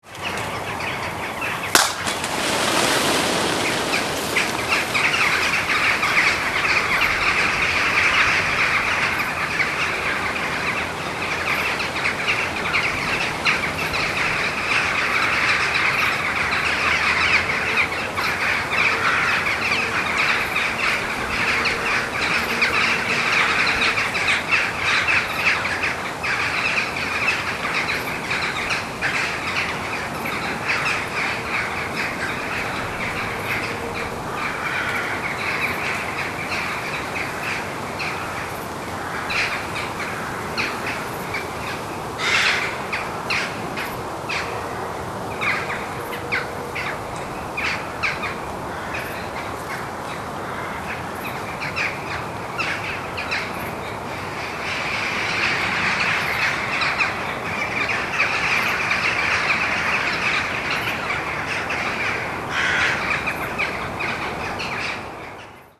Krähencontent
In der Dämmerung sammeln sie sich jetzt an den Weihern, flattern in großen, schwarzen Schwärmen über die Felder und lassen sich zu Hunderten auf Bäumen und Überlandleitungen nieder, wo sie krächzen und die Nacht herbeischwatzen: